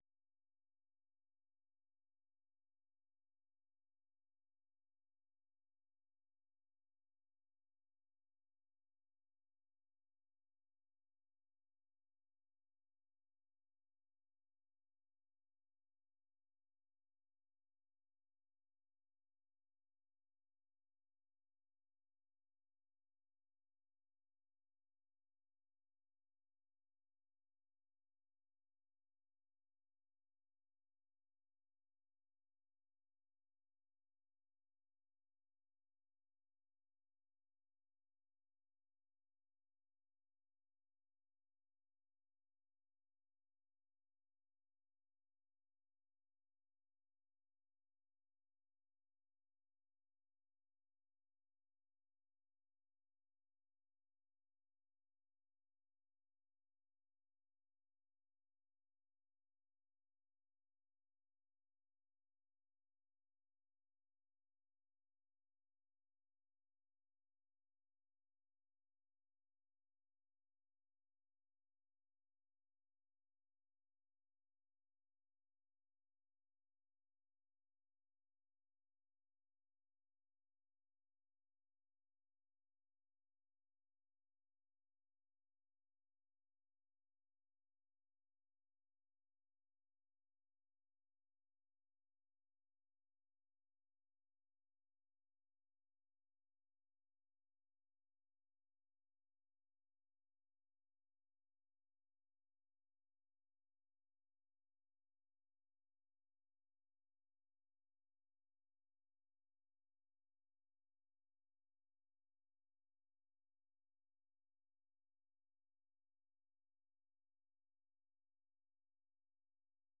ဗွီအိုအေမြန်မာပိုင်းရဲ့ ညပိုင်း မြန်မာစံတော်ချိန် ၉ နာရီမှ ၁၀နာရီအထိ ရေဒီယိုအစီအစဉ်ကို ရေဒီယိုကနေ ထုတ်လွှင့်ချိန်နဲ့ တပြိုင်နက်ထဲမှာပဲ Facebook နဲ့ Youtube ကနေလည်း တိုက်ရိုက် ထုတ်လွှင့်ပေးနေပါတယ်။